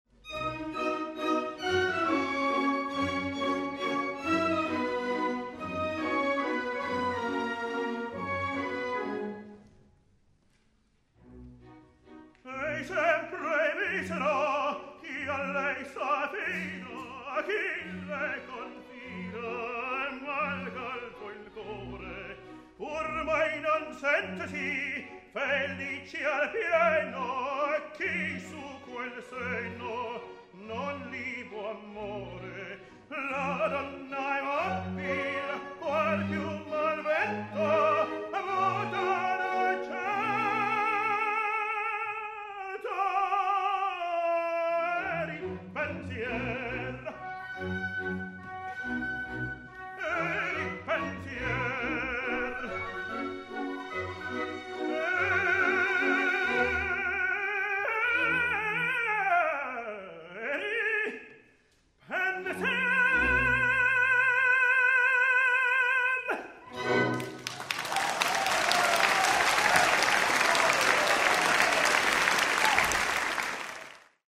Tenor Soloist